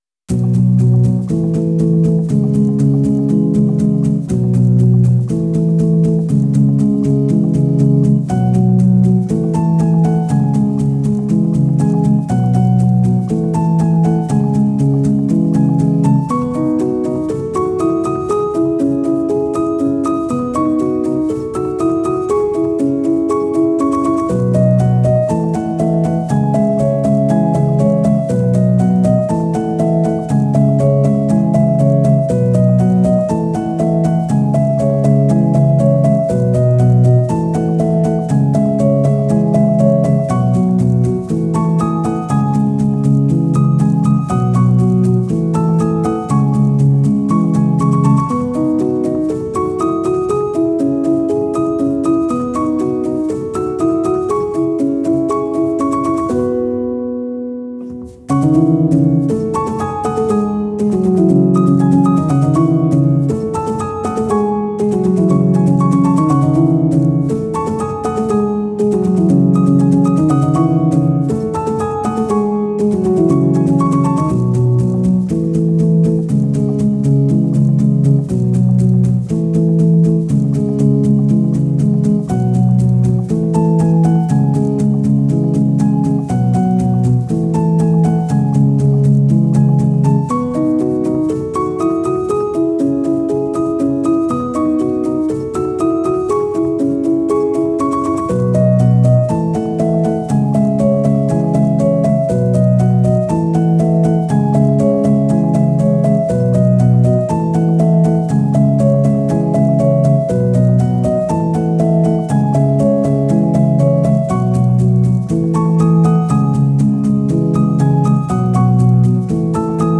PIANO T-Z (21)